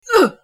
Download Grunt sound effect for free.
Grunt